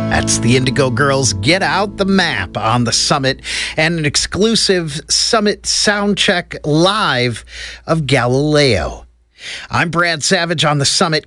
(captured from webcast)
09. announcer (0:11)